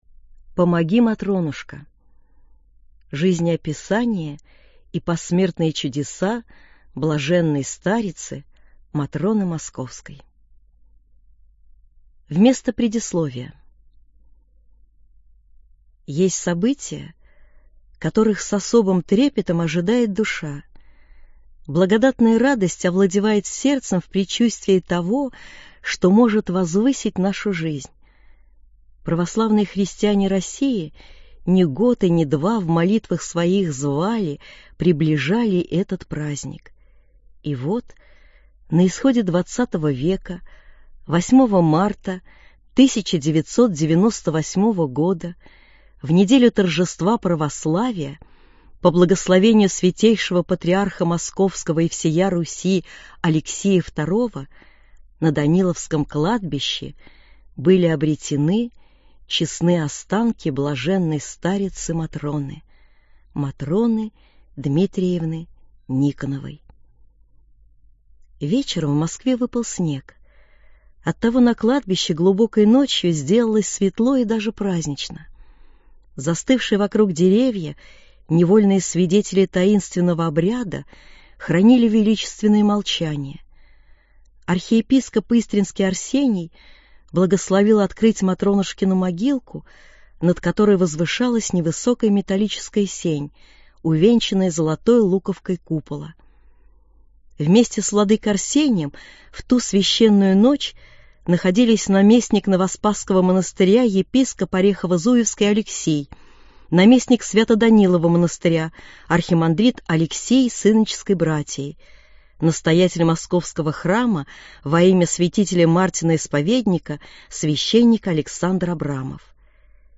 Аудиокнига Житие св.блаженной Матроны Московской | Библиотека аудиокниг